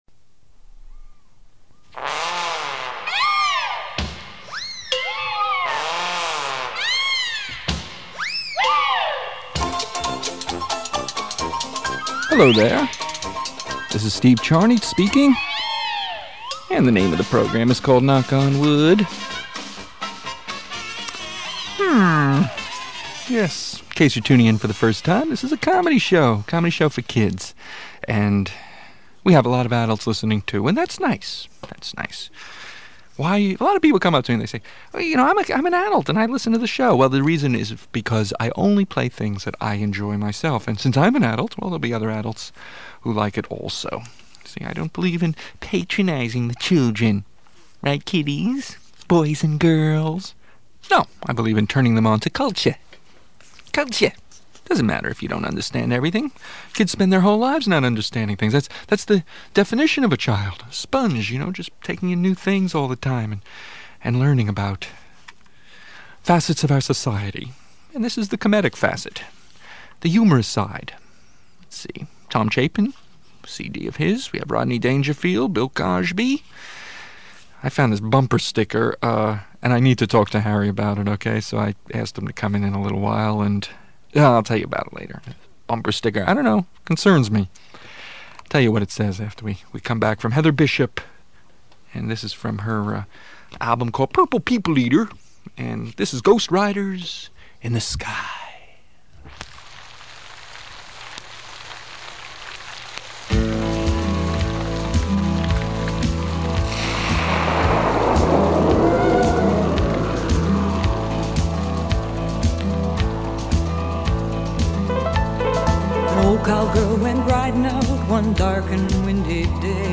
Comedy Show